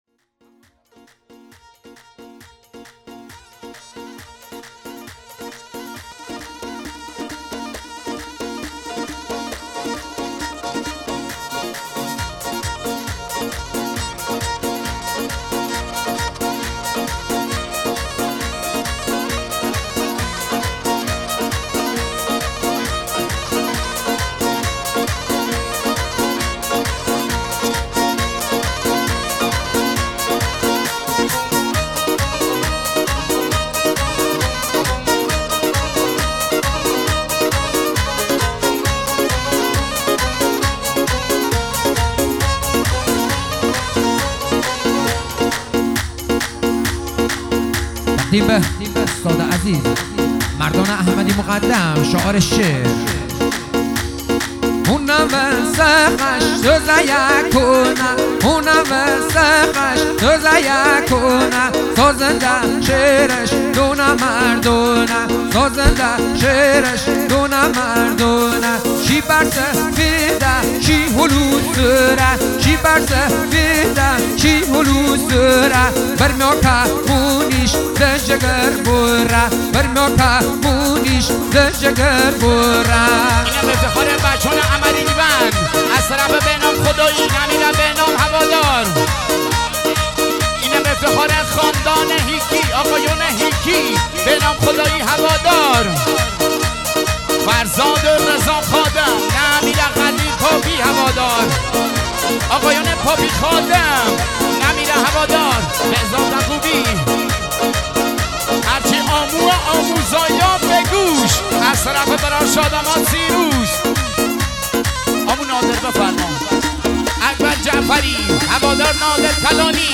ترانه محلی لری